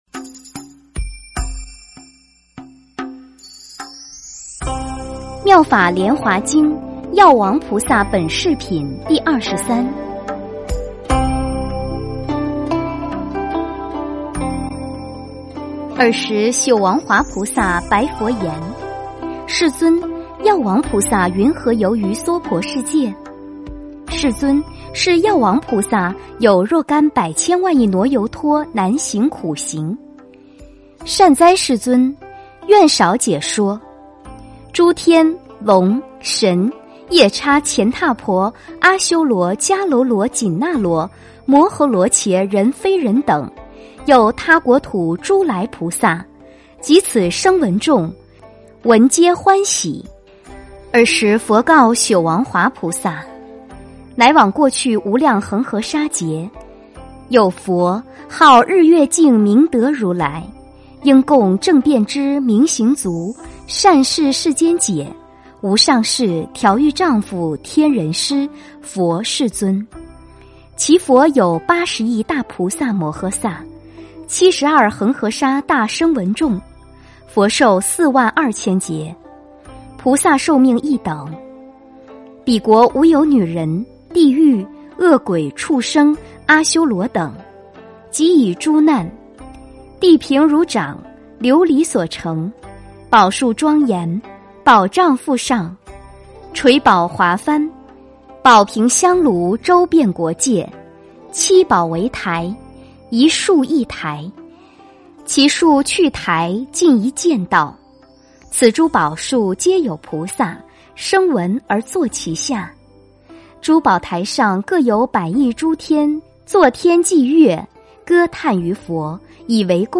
《妙法莲华经》药王菩萨本事品第二十三--佚名 经忏 《妙法莲华经》药王菩萨本事品第二十三--佚名 点我： 标签: 佛音 经忏 佛教音乐 返回列表 上一篇： 药师经 6 (完结)--佚名 下一篇： 圆觉经--未知 相关文章 南无地藏王菩萨圣号--佛教唱颂编 南无地藏王菩萨圣号--佛教唱颂编...